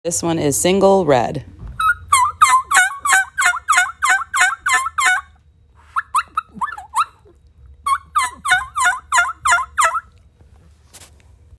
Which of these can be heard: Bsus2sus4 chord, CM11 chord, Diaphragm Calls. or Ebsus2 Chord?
Diaphragm Calls.